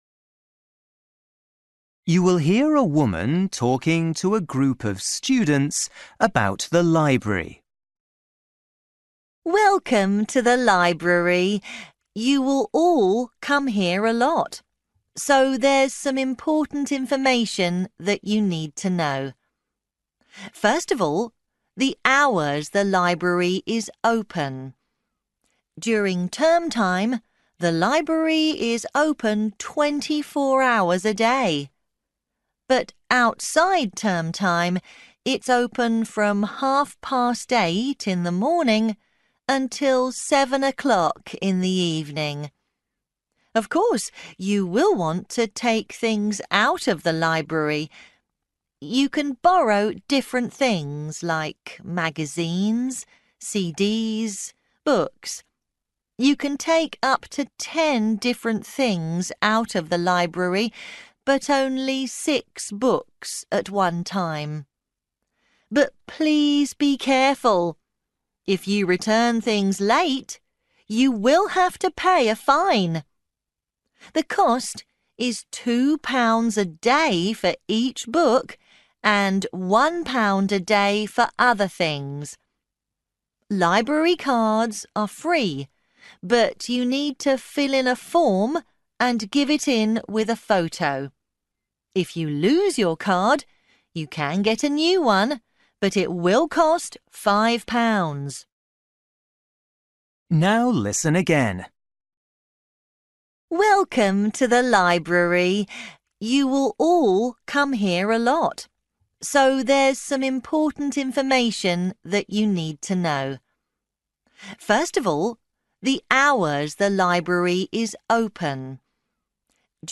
You will hear a woman talking to a group of students about the library.